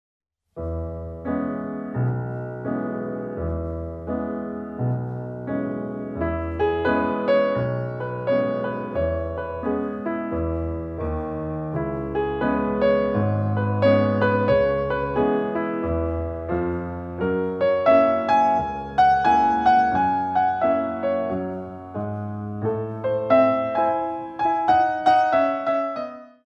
2. Foot Warm-Up At The Barre & Port De Bras